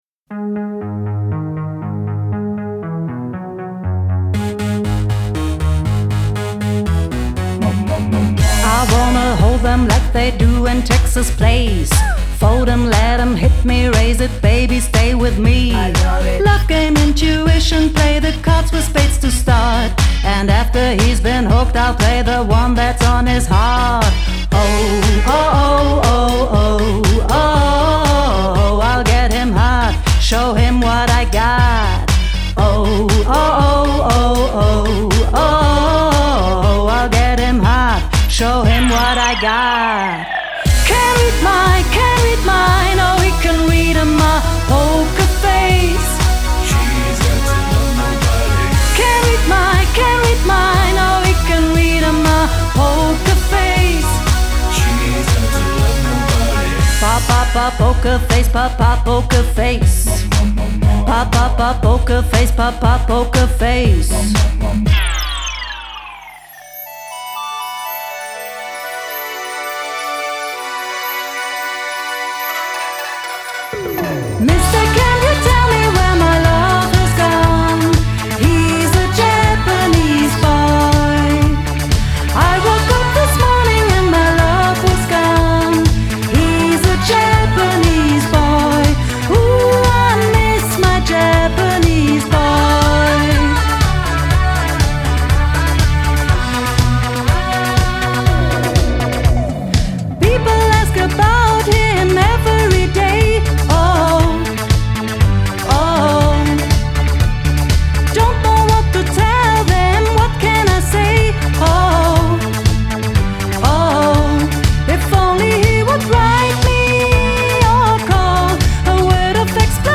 Disco Fox und mehr ...
Ein energiegeladenes Disco-Konzert.
DJ-Musik und Gesangseinlagen wechseln sich ab.